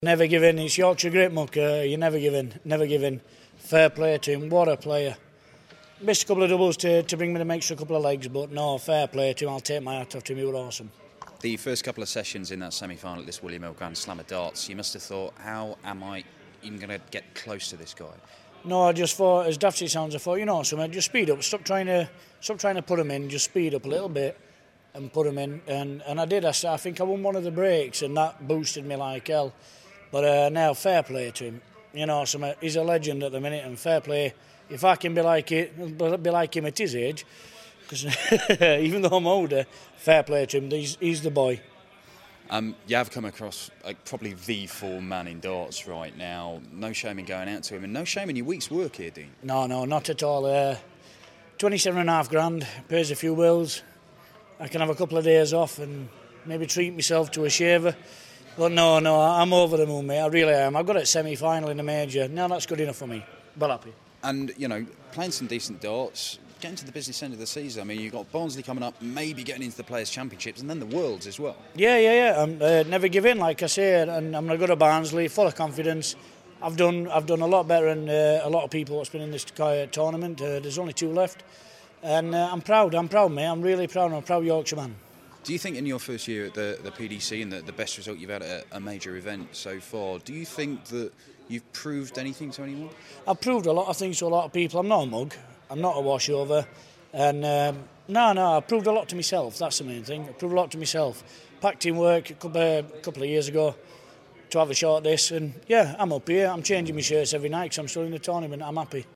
William Hill GSOD - Winstanley Interview (SF)